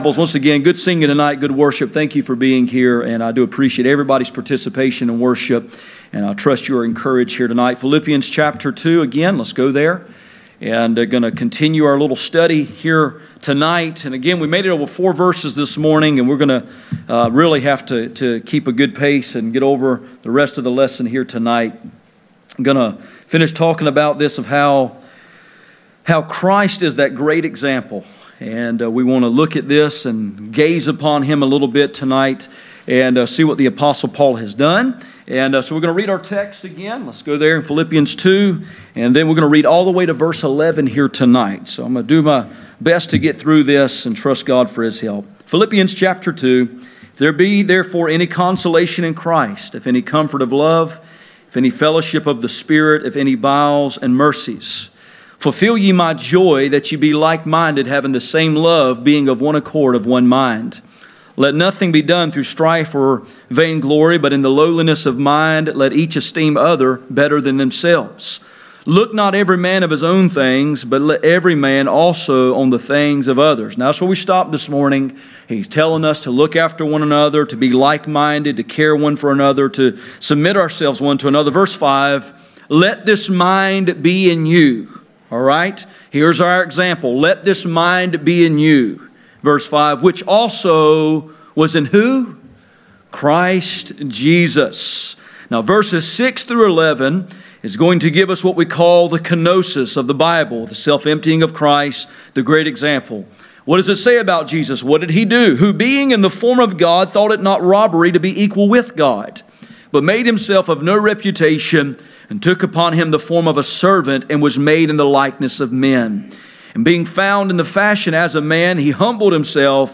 Philippians 2:1-11 Service Type: Sunday Evening %todo_render% « Pursuing the mind of Christ True Worship